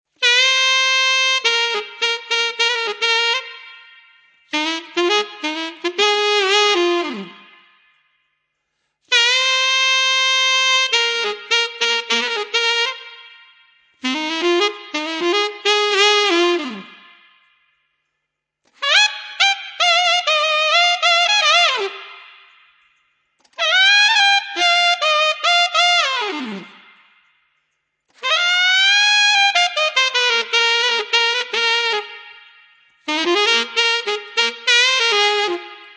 感受次中音独奏循环3
描述：感受男高音独唱
标签： 100 bpm Funk Loops Woodwind Loops 904.00 KB wav Key : Unknown
声道立体声